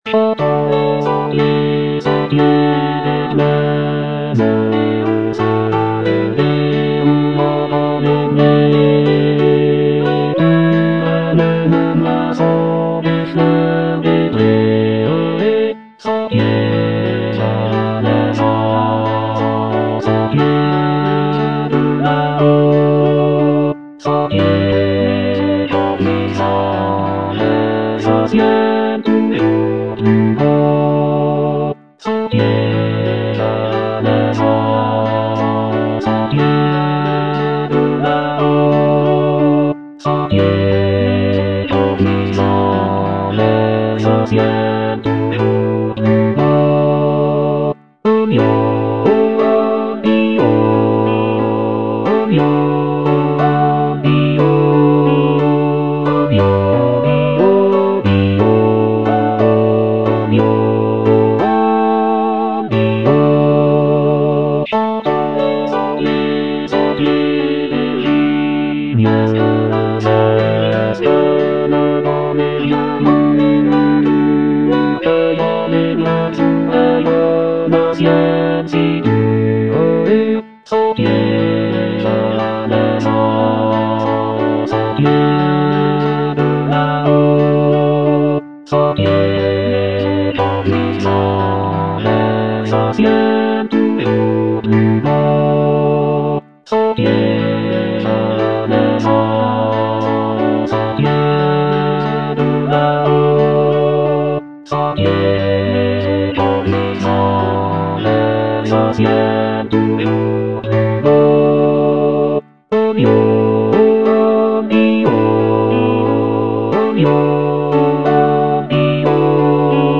Bass II (Emphasised voice and other voices)